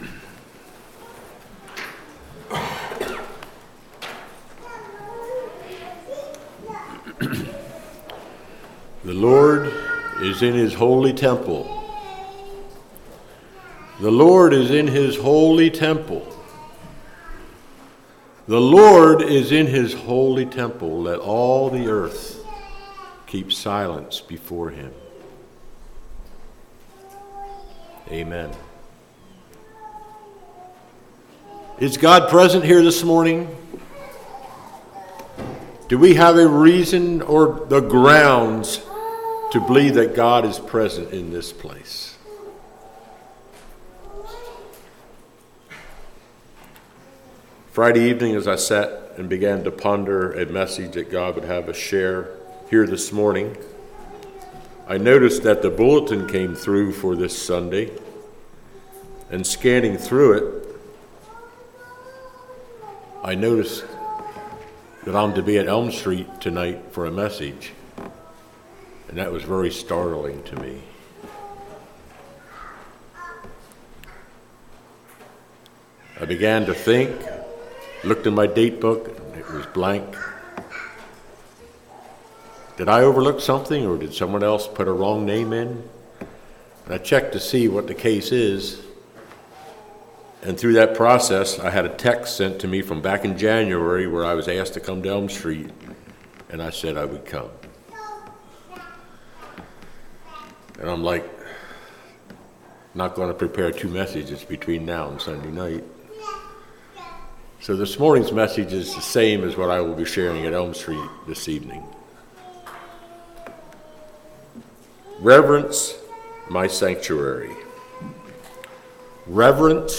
Congregation: Chapel